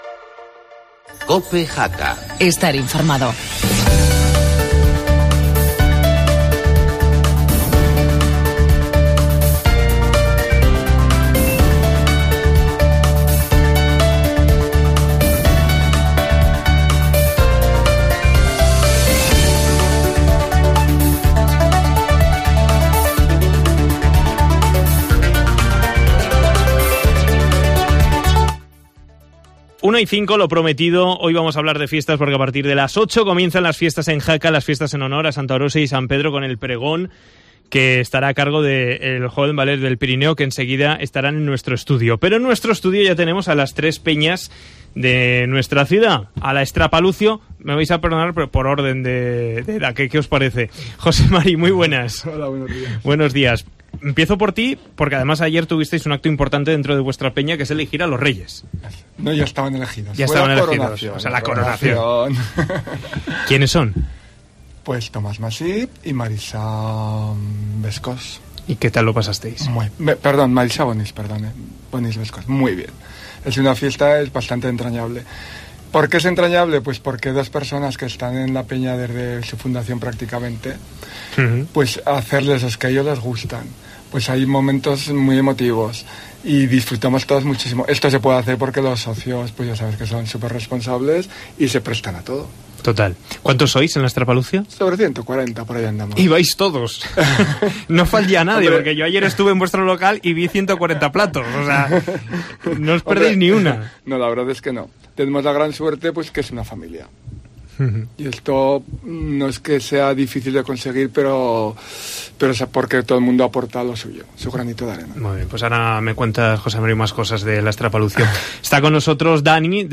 Las peñas de Jaca debaten en COPE